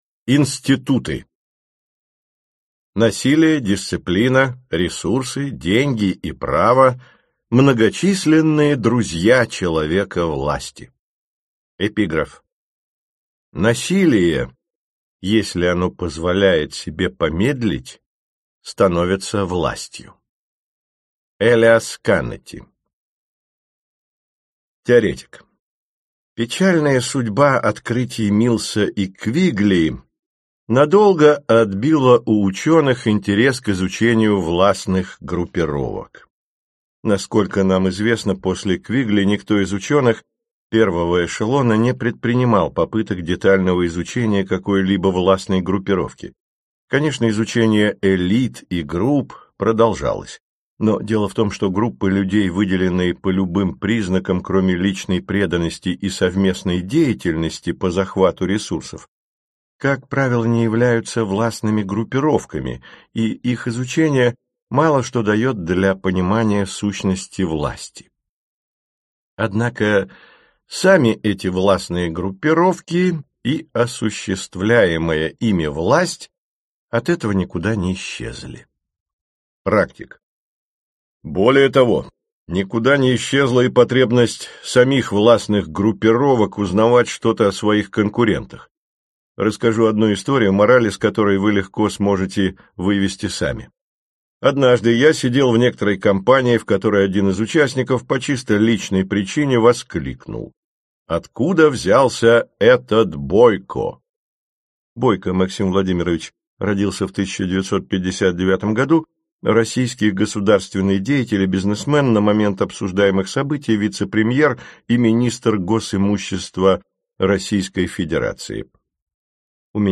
Аудиокнига Лестница в небо. Диалоги о власти, карьере и мировой элите. Часть 5 | Библиотека аудиокниг